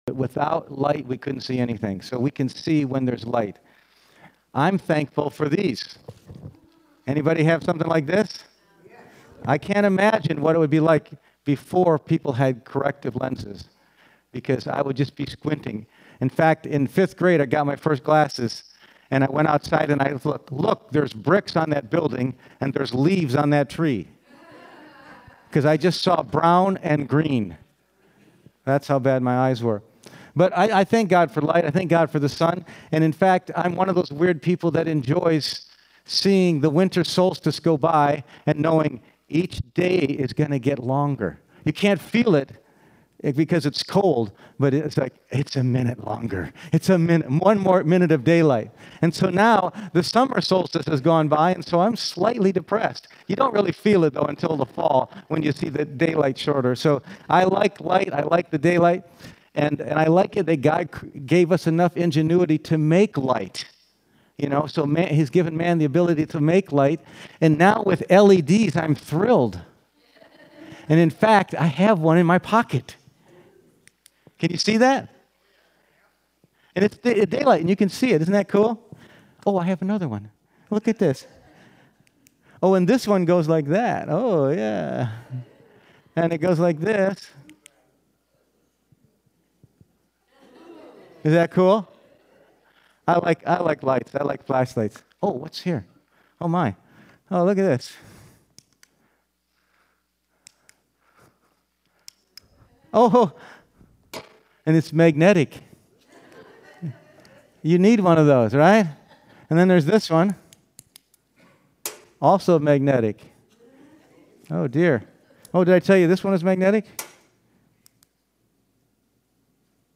"I Am" Statements in John Passage: John 6:35-48, John 8:12, John 9:1-5 Service Type: Sunday Morning %todo_render% « Before Abraham Was